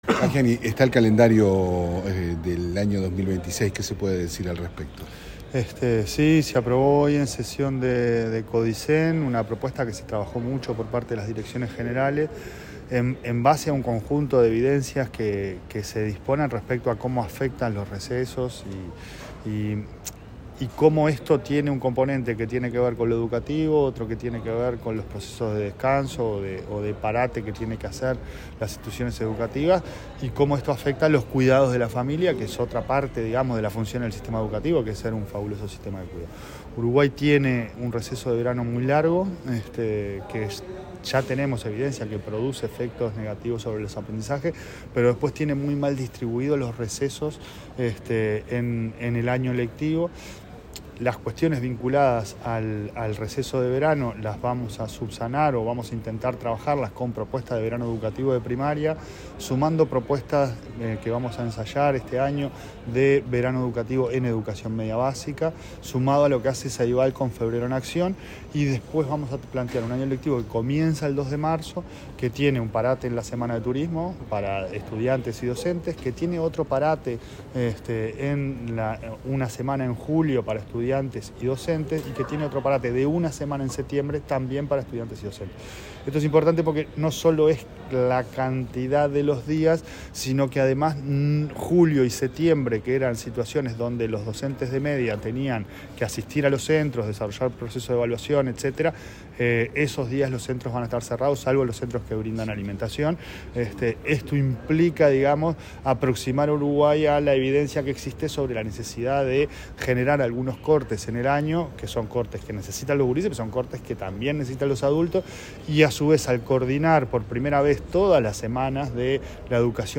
Declaraciones del presidente de la ANEP, Pablo Caggiani
Declaraciones del presidente de la ANEP, Pablo Caggiani 09/12/2025 Compartir Facebook X Copiar enlace WhatsApp LinkedIn El presidente de la Administración Nacional de Educación Pública (ANEP), Pablo Caggiani, fue entrevistado para medios informativos, sobre el calendario lectivo 2026.